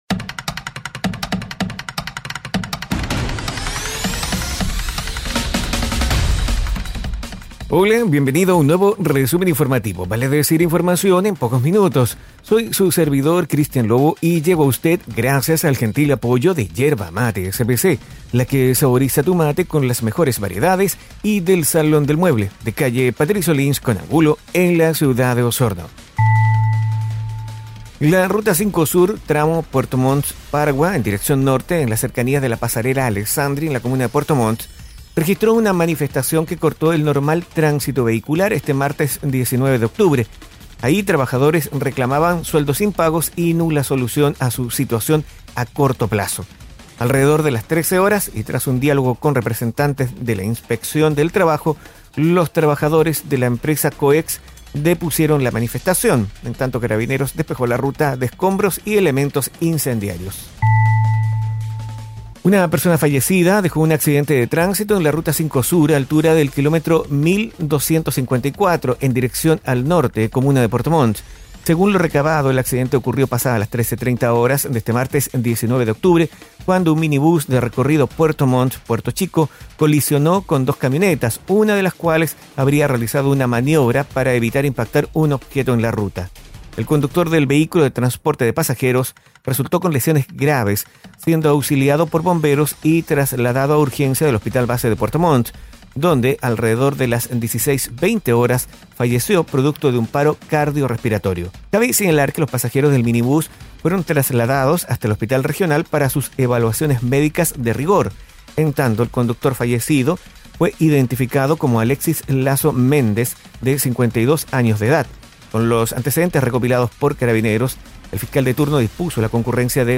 Informaciones y noticias enfocadas en la Región de Los Lagos. Difundido en radios asociadas.